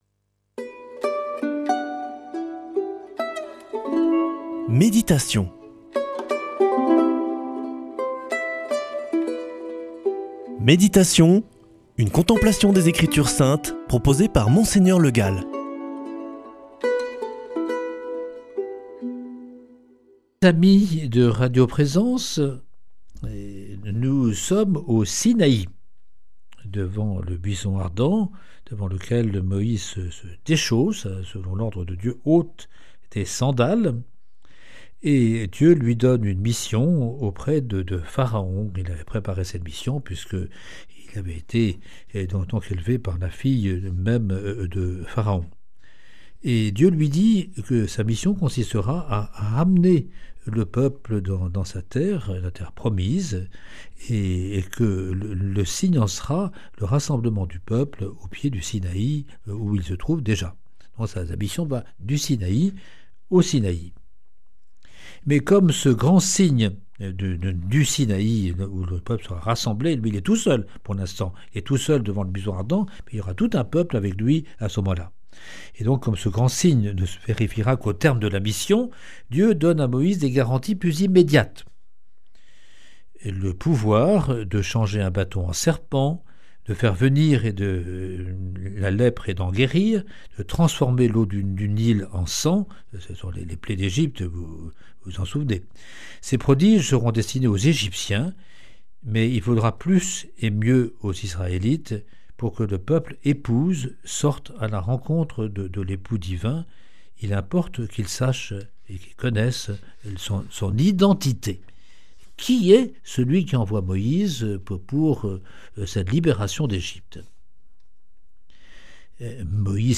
lundi 19 mai 2025 Méditation avec Monseigneur Le Gall Durée 7 min
Présentateur